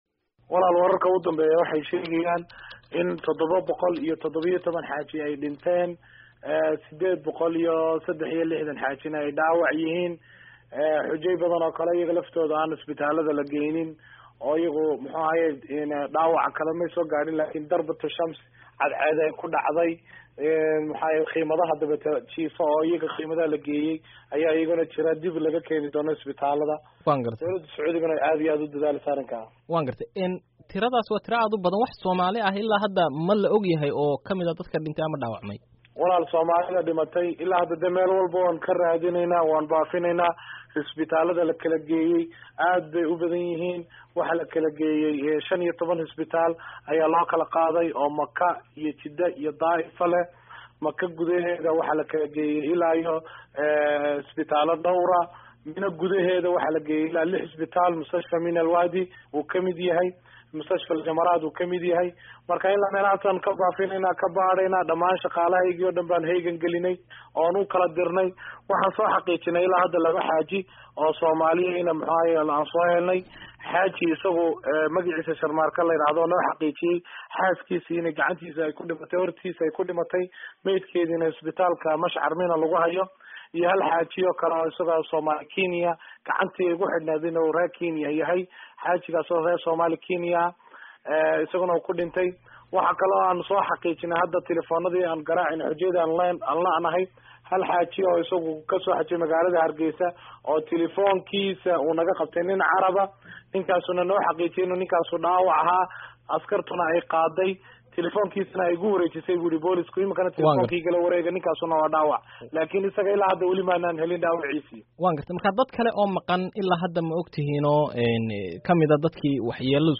Wareysi Xaj1